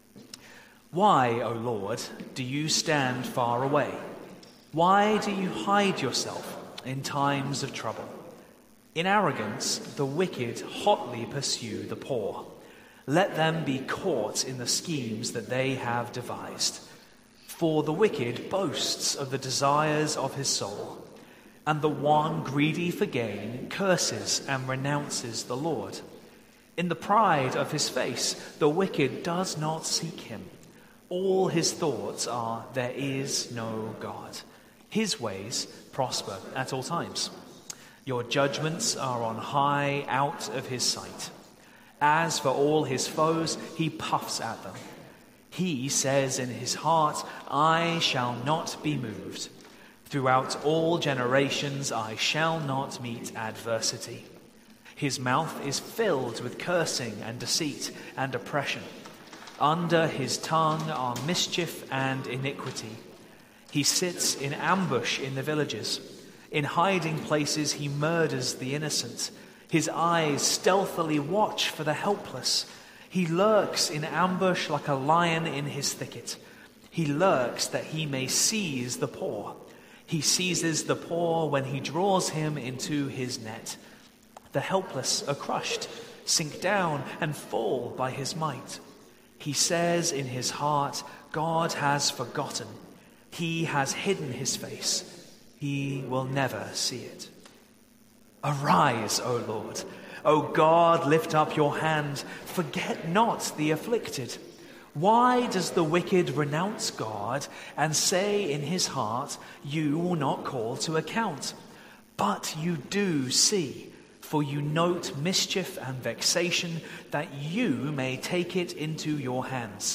Sermon Series: The Psalms